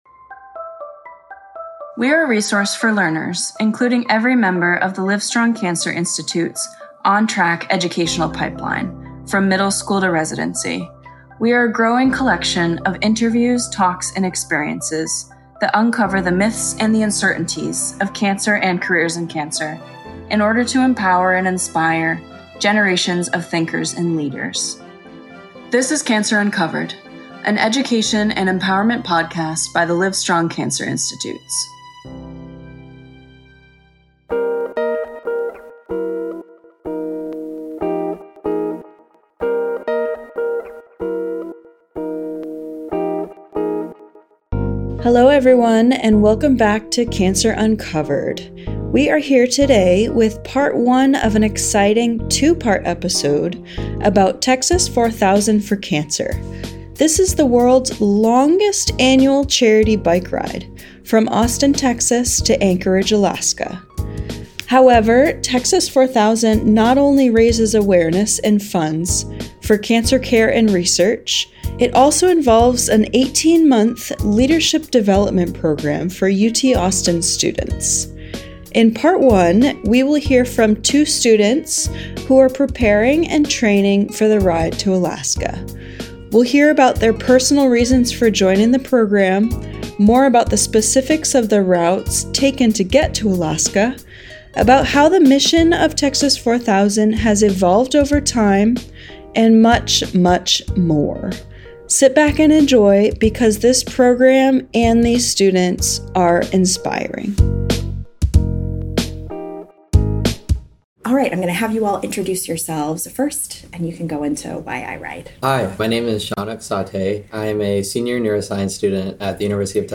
In Part 1 of this two-part episode series, two students at the University of Texas at Austin share their experience in the Texas 4000 for Cancer program, which provides leadership development and training to prepare students to bike from Austin, Texas, to Anchorage, Alaska, in order to raise funds and awareness for cancer.